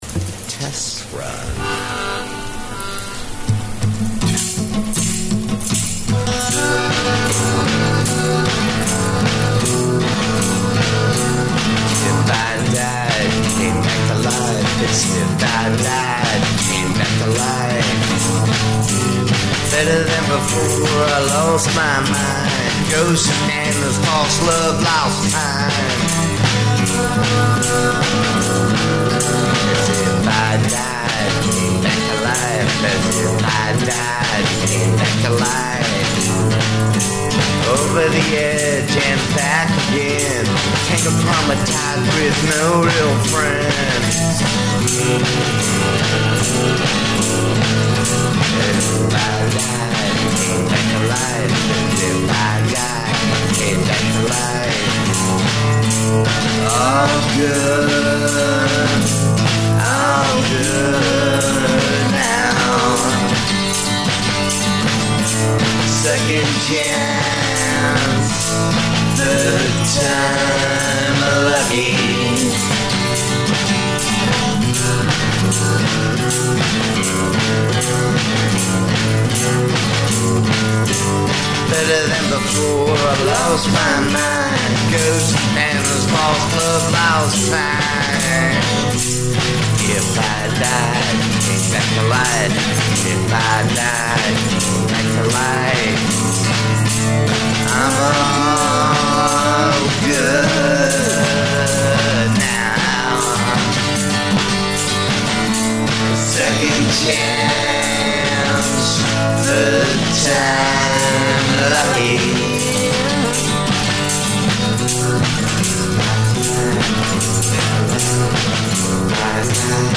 Just wrote this new quirky song.